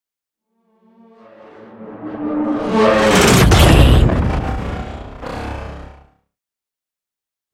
Sci fi vehicle whoosh and hit
Sound Effects
futuristic
intense
woosh to hit